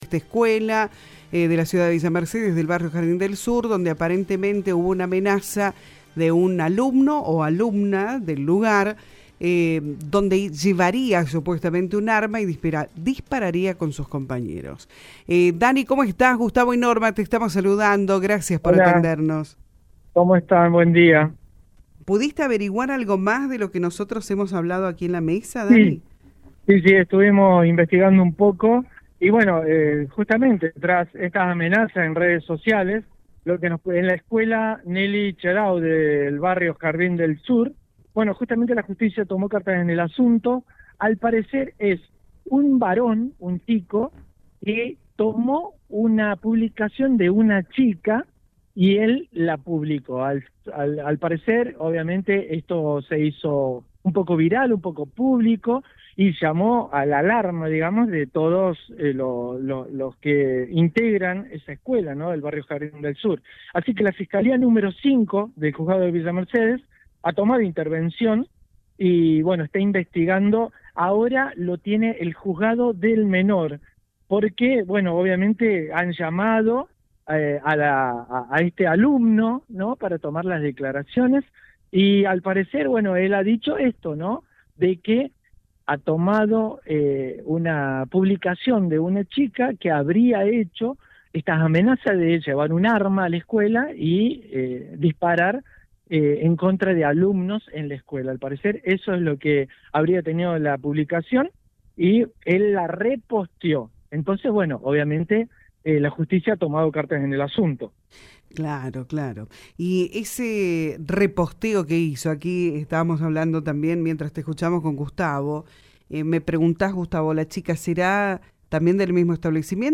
🎙 Conducción